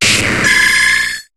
Cri d'Octillery dans Pokémon HOME.